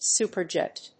アクセント・音節súper・jèt